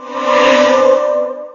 Magic2.ogg